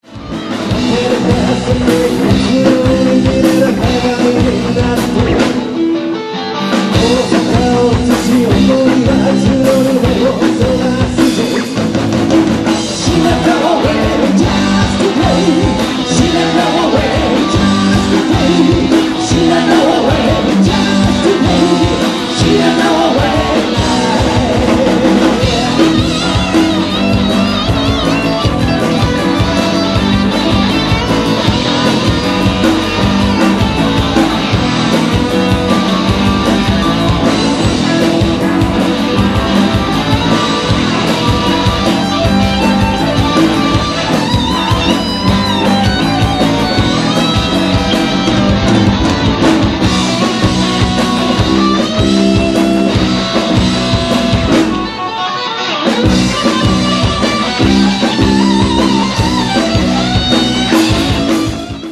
30年目の最後のライブです。
guitar,keybords,chorus
bass,chorus
drums